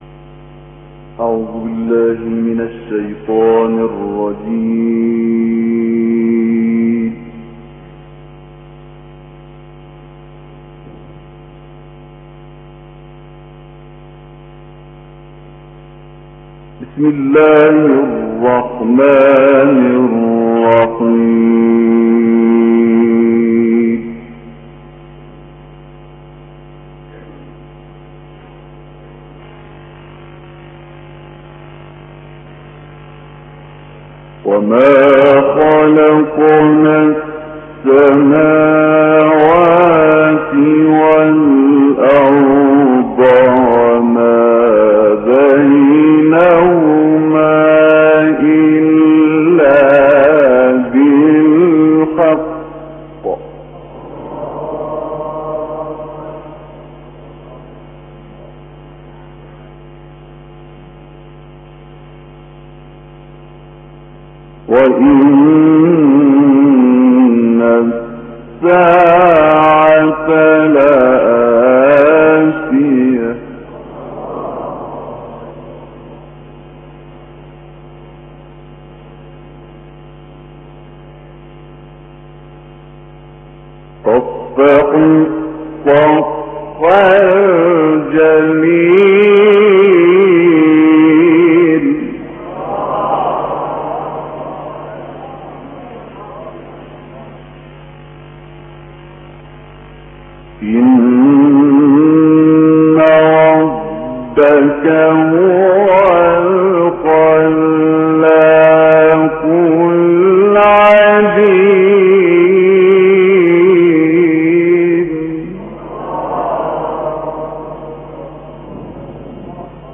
enregistrement en mosquée
Recitation in the readings of Hafs et Warsh. style : tajwîd.